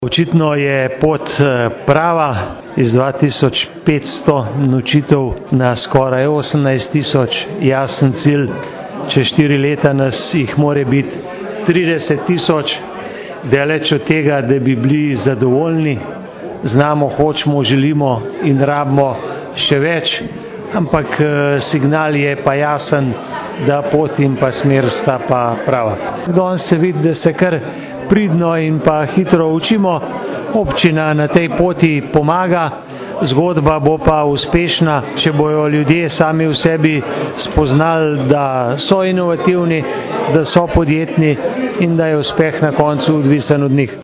izjava_mag.borutsajoviczupanobcinetrzic_turizem.mp3 (1,1MB)